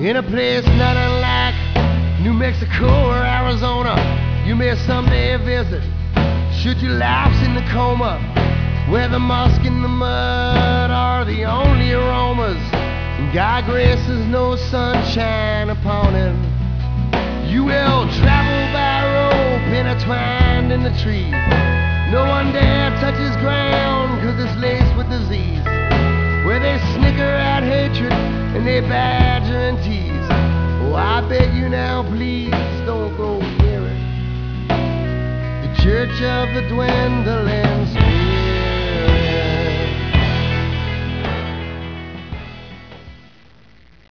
Recorded, mixed, and mastered
446K .wav(mono)